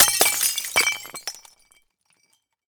glass_fall03hl.ogg